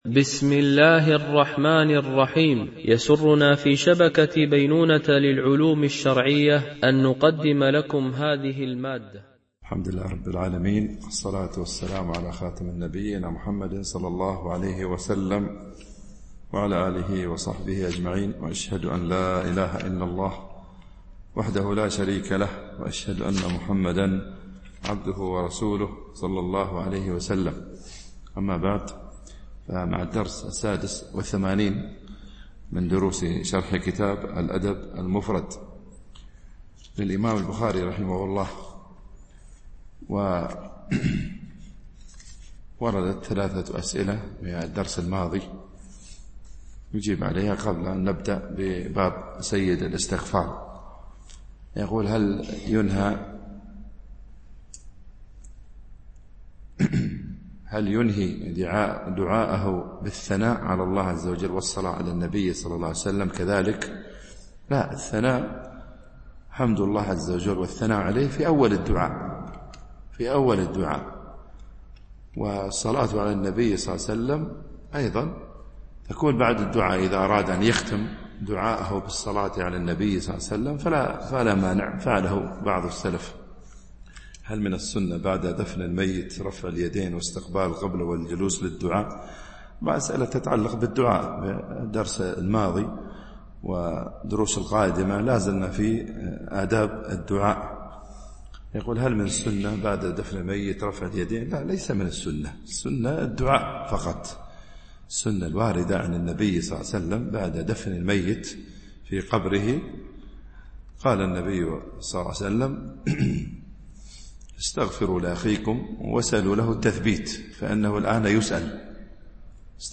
شرح الأدب المفرد للبخاري ـ الدرس 86 ( الحديث 617 - 622 )